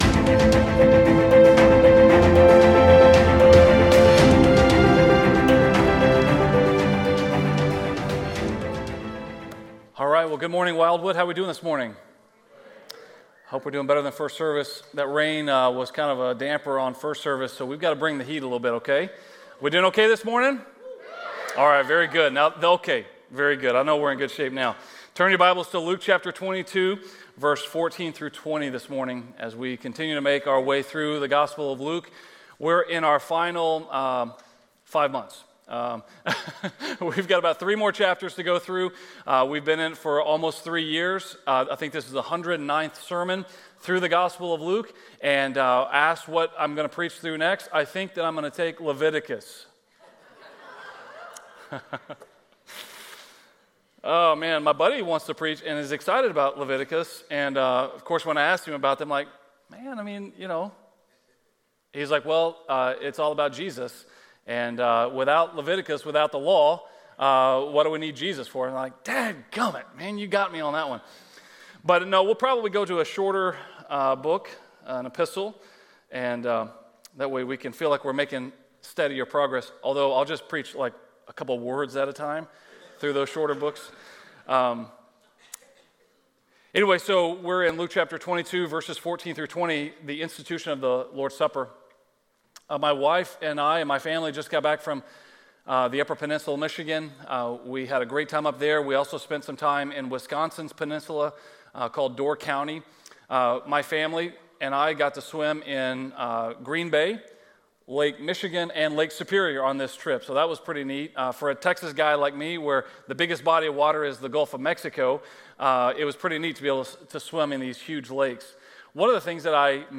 A message from the series "Stand Alone Sermons."